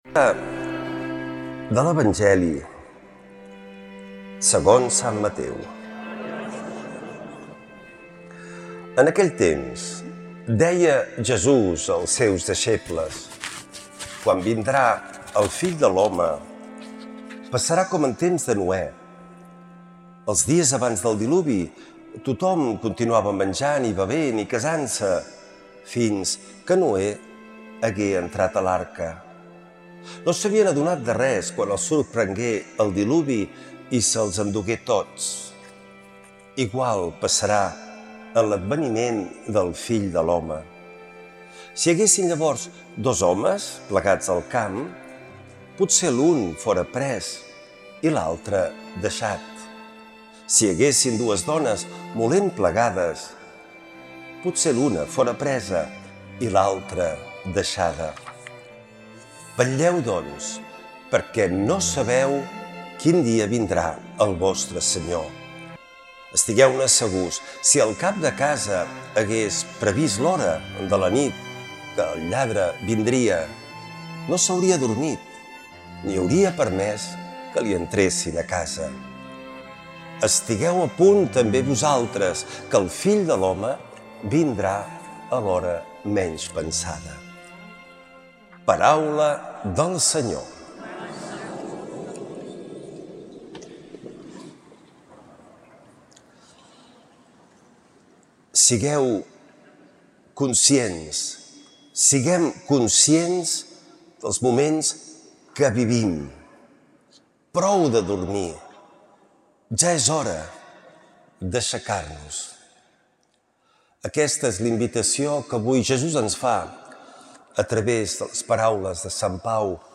Lectura de l’Evangeli segons Sant Mateu.